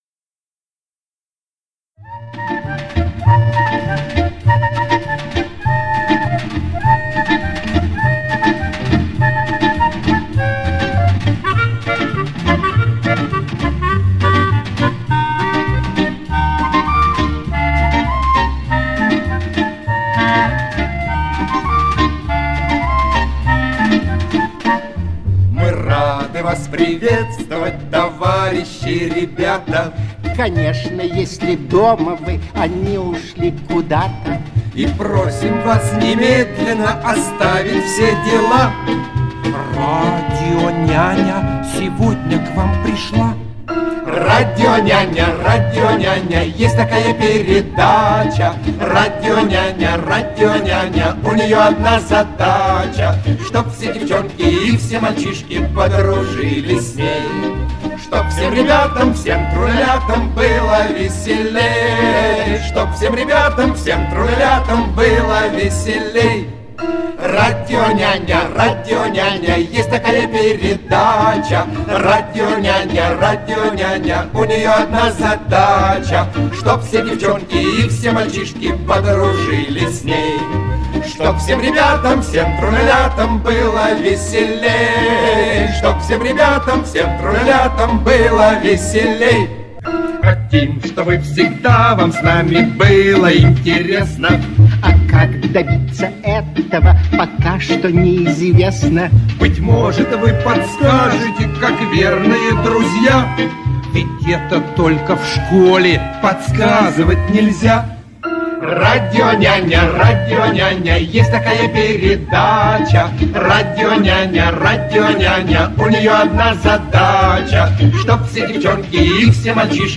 Соединил куплеты в песне.